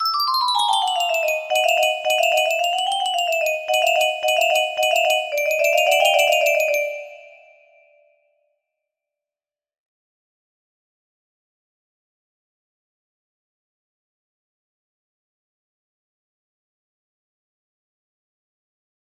Abigail's music melody music box melody